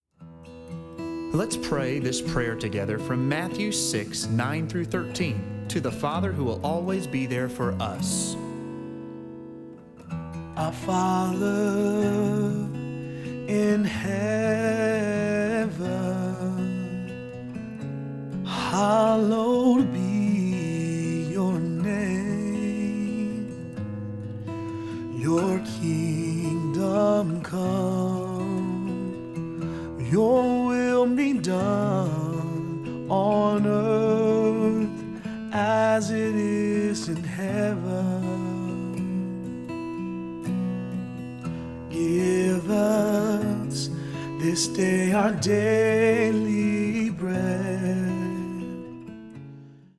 Acoustic Remix